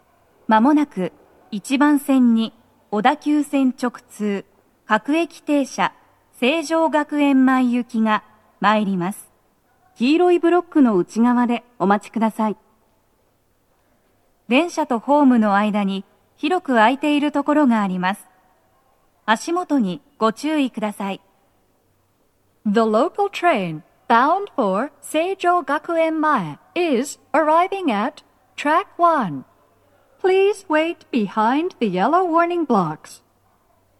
スピーカー種類 BOSE天井
鳴動は、やや遅めです。
女声
接近放送2